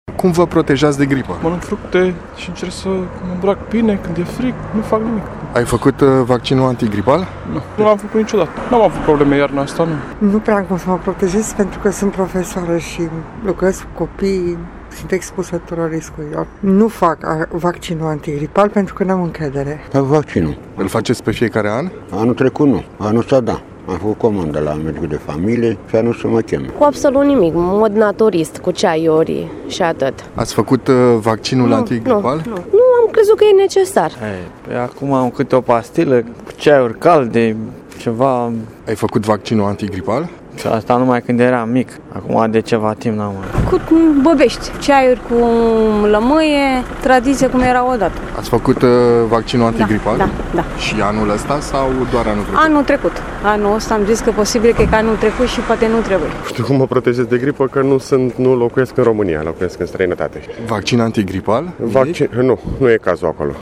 VOX-GRIPA.mp3